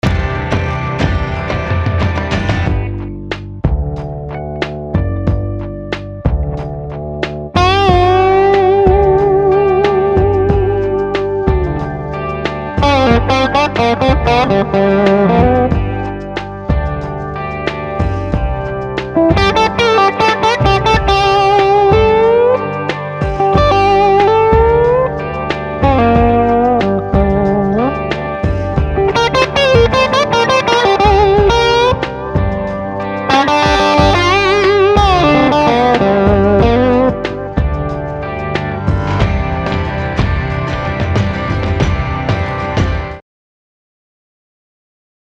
Der Front-Pickup scheint in jeder Note ein „Ü“ zu offenbaren, das so typisch für Peter-Green- oder Dickey-Betts-Sounds ist. Einmalig auch die Übertragung jedes Anschlags mit einem unverkennbaren Schmatzer oder Klacken.
Unabhängig von der Reglerstellung oder des Gain-Faktors ließ dieses Set immer eine gewisse Verwandschaft zum Tele-Sound durchblicken: Atmende, offene Höhen, ein beißender, hohler Twang, der jedoch nie unangenehm harsch wurde.
Dafür besitzen sie einen Peak in den hohen Mitten, der nur schwer und meist mit Hilfe des Ton-Potis zu bewältigen ist.
Unterm Strich: Super-Clean, wenig Output, wenig Mitten, sehr höhenreich, aber unvergleichlich dynamisch und antrittsstark.